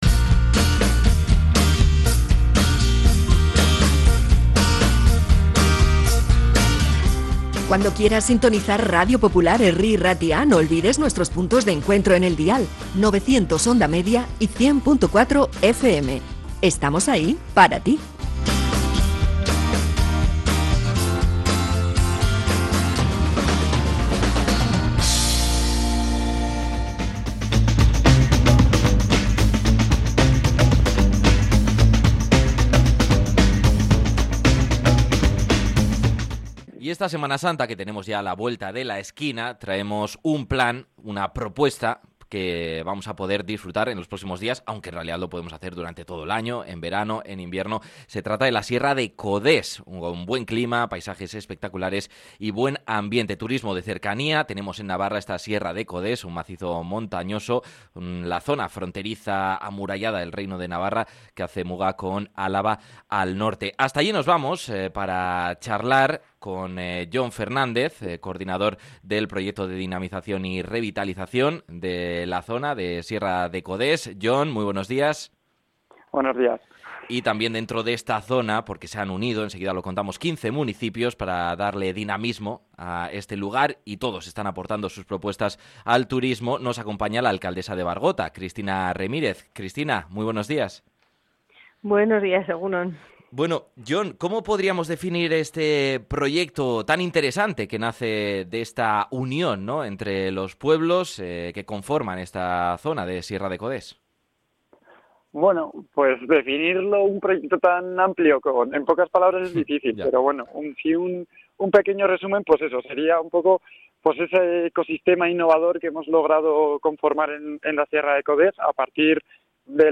SIERRA-DE-CODES-ENTREVISTA-12-04.mp3